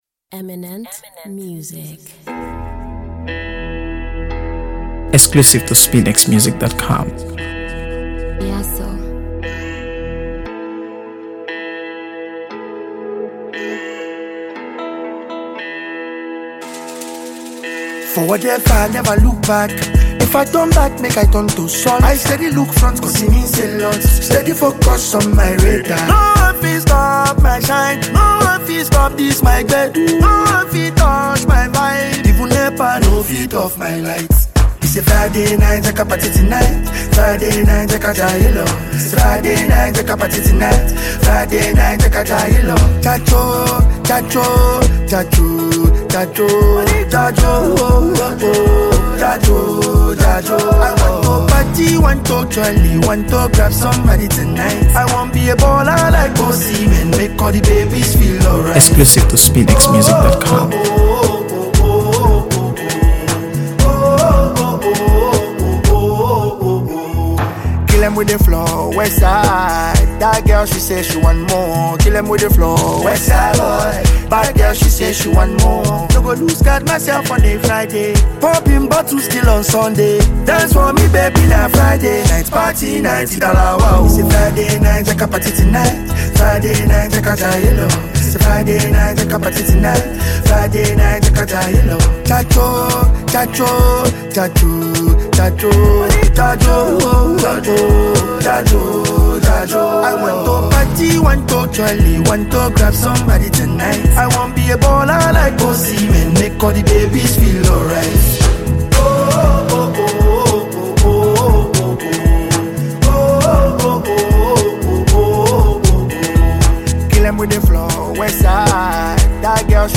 AfroBeats | AfroBeats songs
vibrant Afrobeats anthem
catchy hook, rhythmic bounce, and feel-good vibe